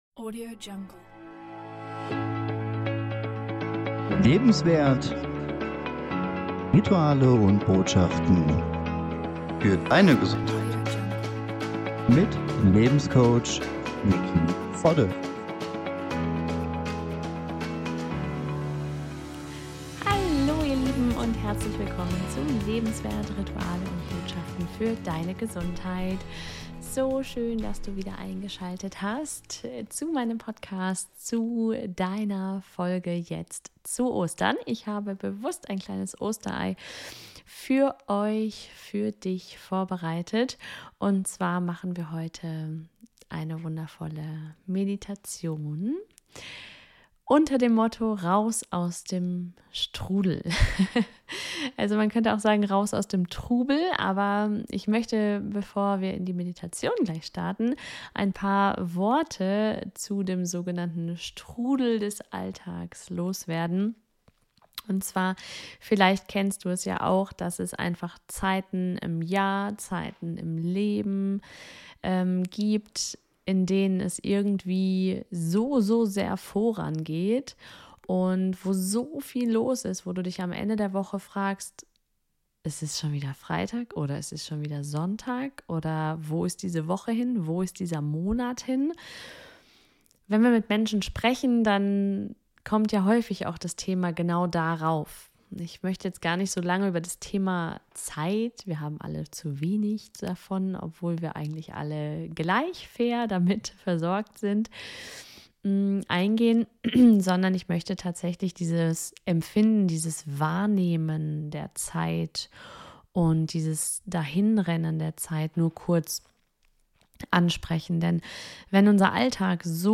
Ein wunderschönes Werkzeug zum Aussteigen aus dem Alltagsstress. Über eine Körperbetonte Meditation, angelehnt an eine abgewandelte Feldenkrais - Übung, findest du in wenigen Minuten den Ausstieg und damit den Einstieg in den gegenwärtigen Moment.